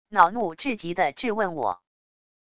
恼怒至极的质问我wav音频